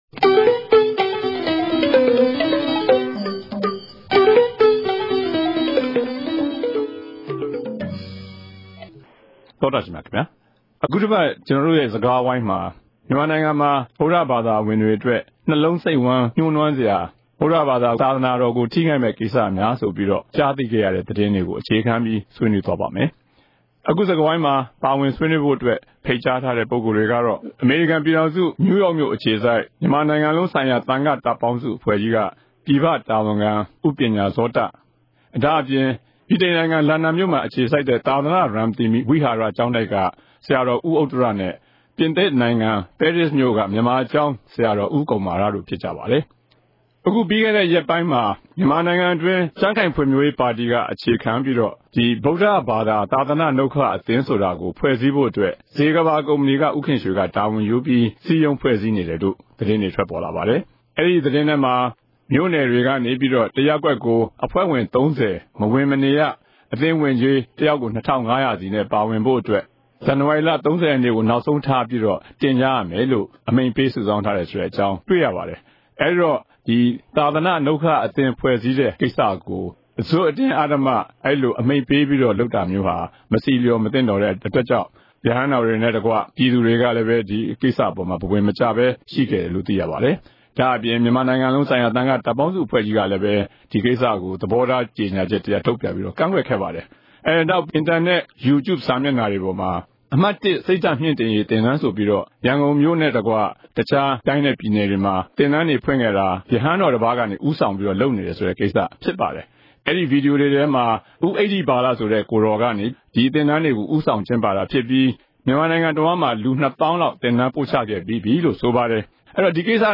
တနင်္ဂနွေစကားဝိုင်း။